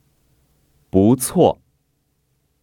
[búcuò] 부추오